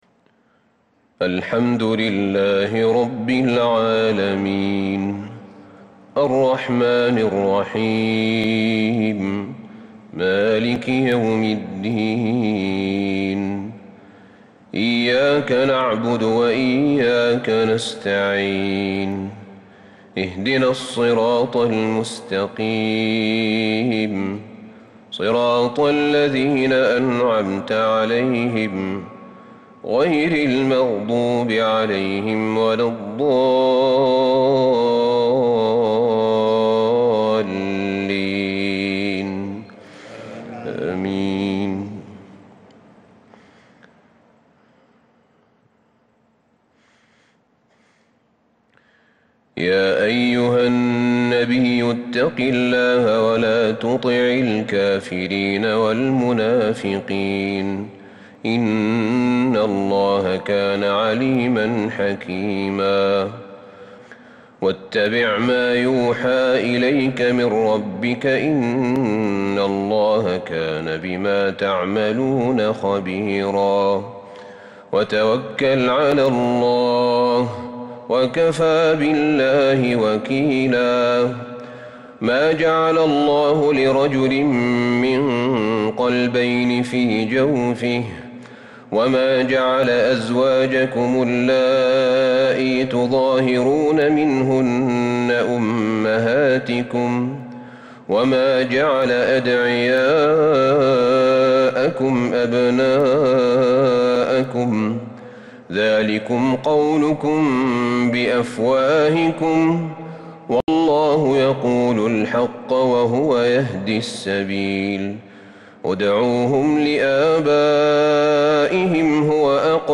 صلاة التهجّد | ليلة 24 رمضان 1442 سورة الأحزاب 1-52 | Tahajjud prayer The night of Ramadan 24 1442 | Surah Al-Ahzab > تراويح الحرم النبوي عام 1442 🕌 > التراويح - تلاوات الحرمين